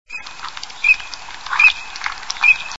Sarcelle d'hiver
Anas crecca
sarcelle.mp3